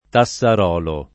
[ ta SS ar 0 lo ]